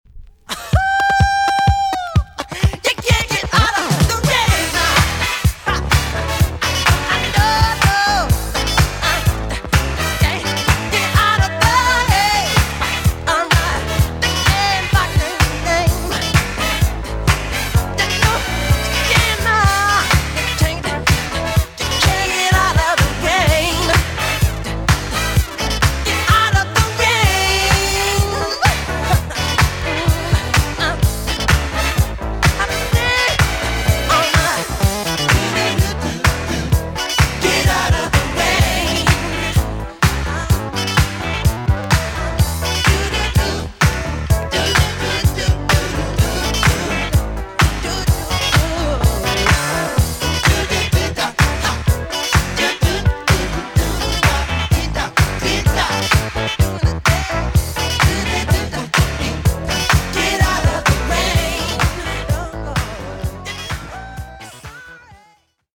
EX 音はキレイです。